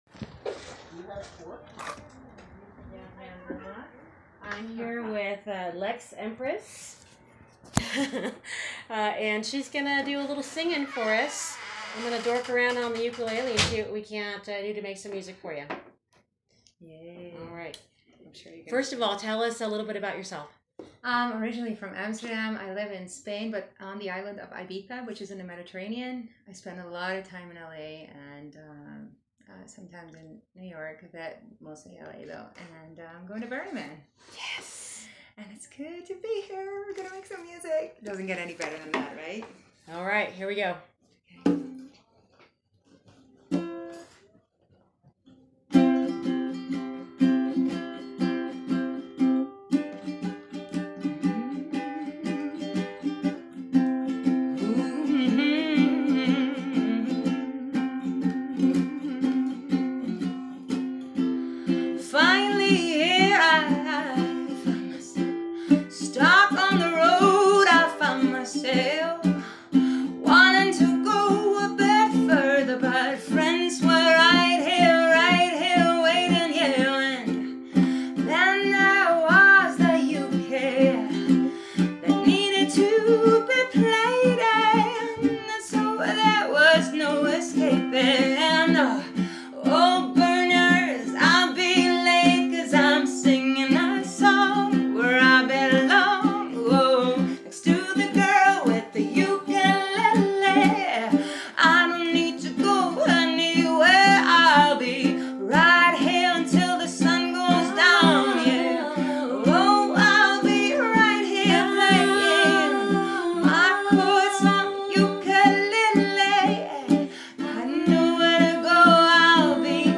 Since we had nothing but time, I started interviewing people live on the radio. Live music began happening spontaneously, so I pulled out my ukulele.
That’s when a tall blonde goddess with a Dutch accent approached me. She was a singer and agreed to sing live if I would accompany her on my uke. She improvised the lyrics on the spot, and I accompanied her with back up vocals.